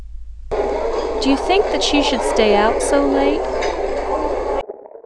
Signal 1 With White Noise
late_cafe.wav